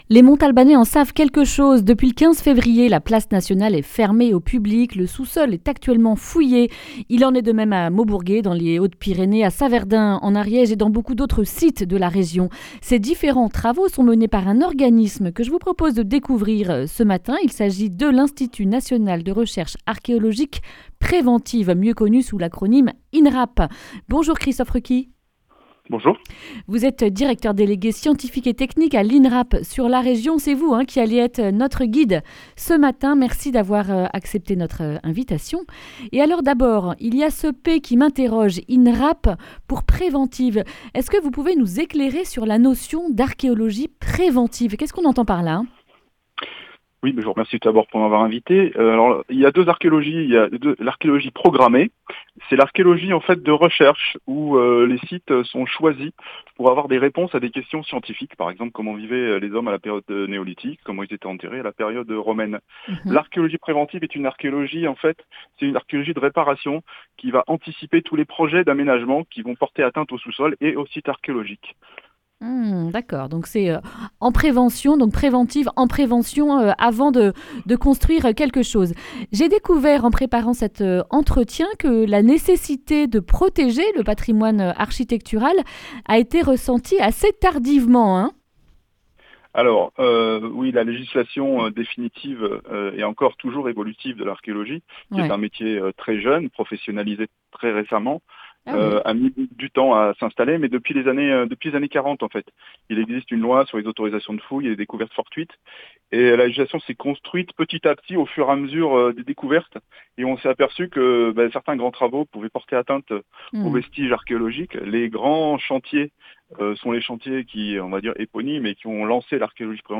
Accueil \ Emissions \ Information \ Régionale \ Le grand entretien \ A la découverte de l’INRAP...